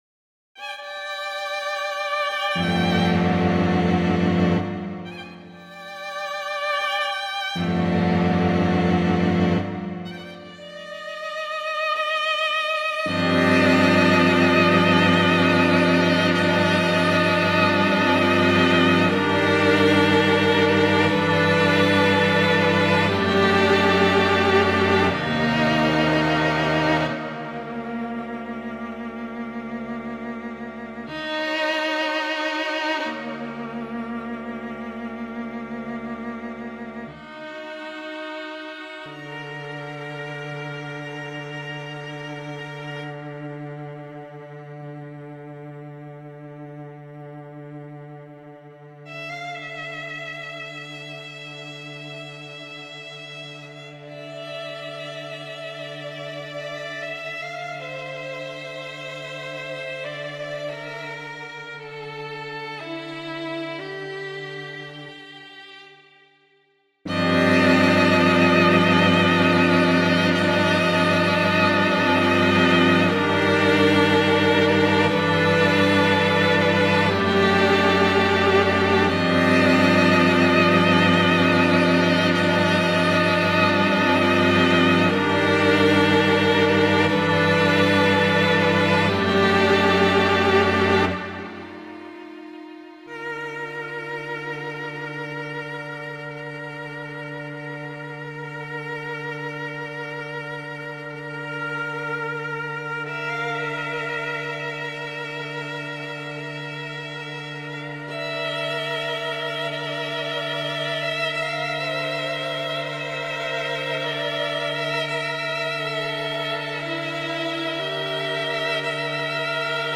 #93 "Ano'ton, awash", short microtonal string quartet (Audio and score)
Had the start floating around in my mind when I woke up, but the rest (especially the microtonality, my first microtonal harmony ever :D) came as part of the process.